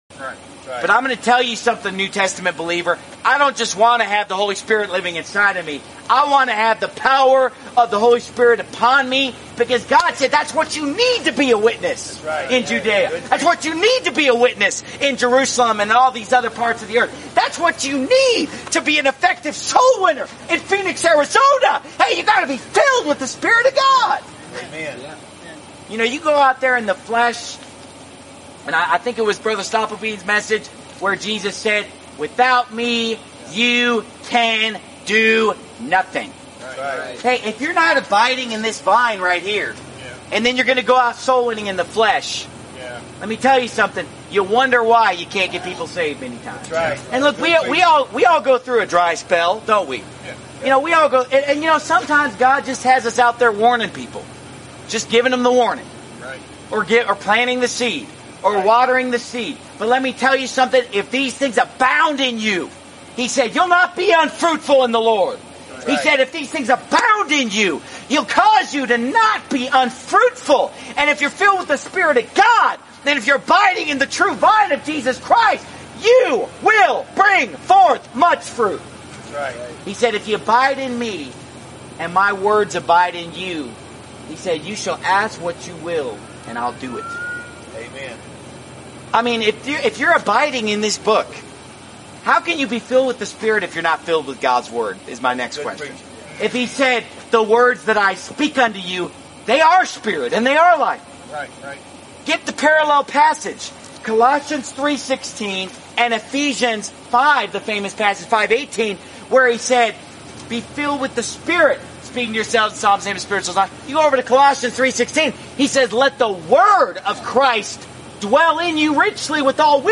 Sermon Clips Pastor Steven Anderson